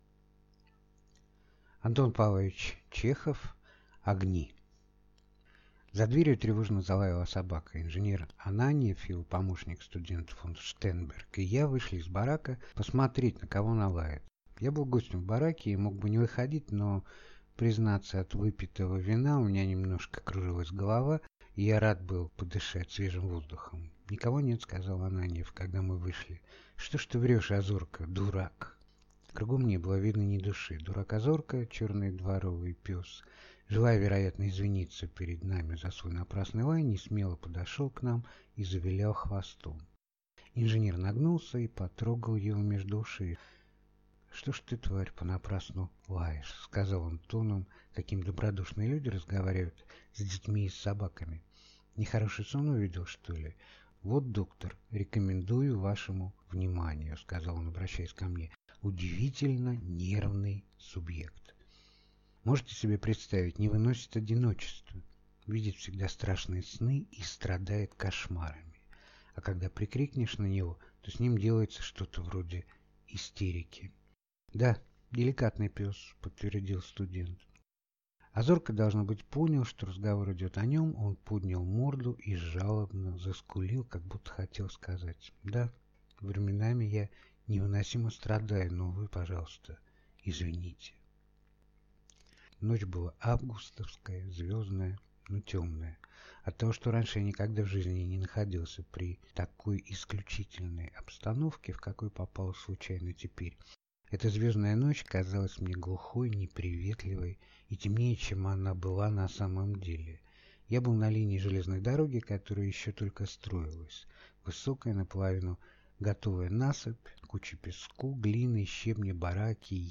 Аудиокнига Огни | Библиотека аудиокниг